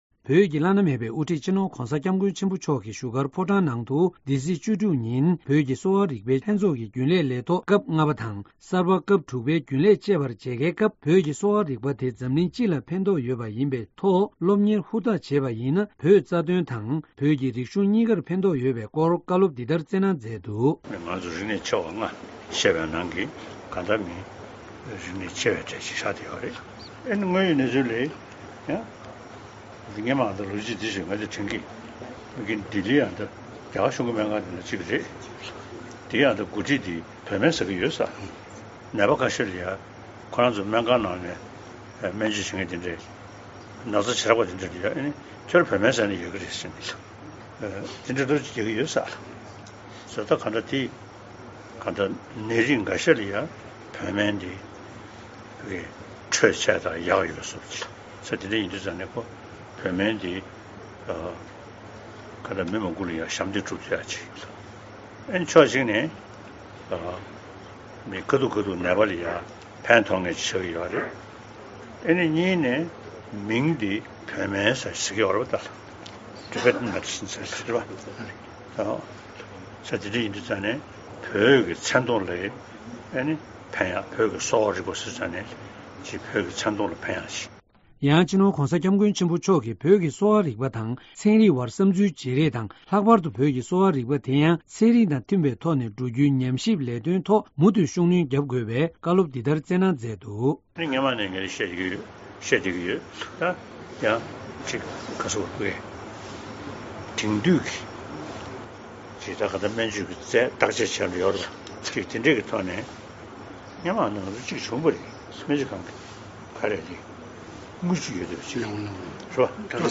བོད་ཀྱི་དབུ་ཁྲིད་༧གོང་ས་མཆོག་གིས་བོད་ཀྱི་གསོ་བ་རིག་པའི་ལྷན་ཚོགས་ཀྱི་རྒྱུན་ལས་ཚོར་མཇལ་ཁའི་སྐབས། ༢༠༡༩།༩།༡༦ བོད་ཀྱི་དབུ་ཁྲིད་༧གོང་ས་མཆོག་གིས་བོད་ཀྱི་གསོ་བ་རིག་པའི་ལྷན་ཚོགས་ཀྱི་རྒྱུན་ལས་ཚོར་མཇལ་ཁའི་སྐབས། ༢༠༡༩།༩།༡༦
སྒྲ་ལྡན་གསར་འགྱུར། སྒྲ་ཕབ་ལེན།